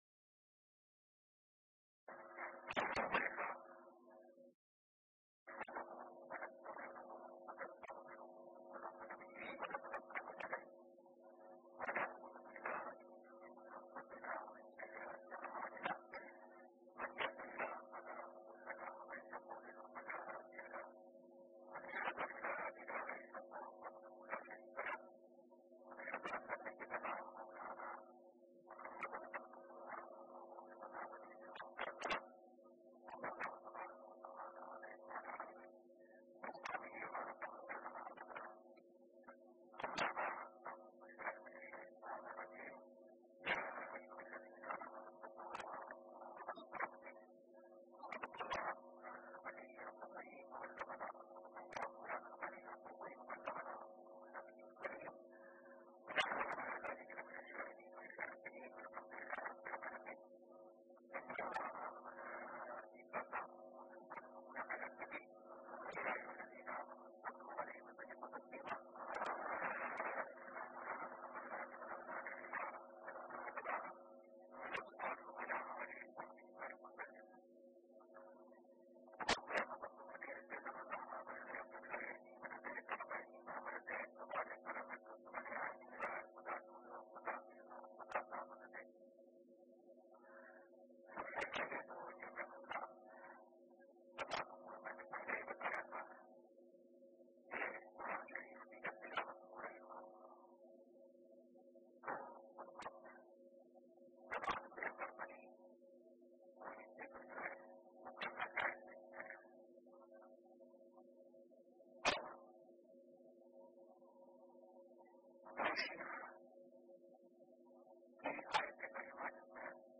نیکوں کا راستہ ZiaeTaiba Audio میڈیا کی معلومات نام نیکوں کا راستہ موضوع تقاریر آواز تاج الشریعہ مفتی اختر رضا خان ازہری زبان اُردو کل نتائج 836 قسم آڈیو ڈاؤن لوڈ MP 3 ڈاؤن لوڈ MP 4 متعلقہ تجویزوآراء